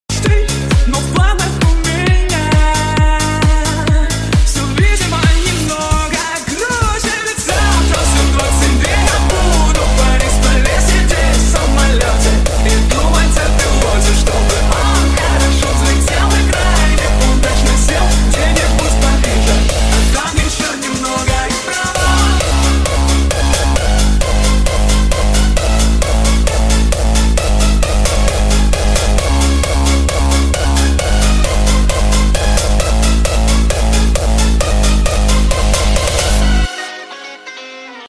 hardstyle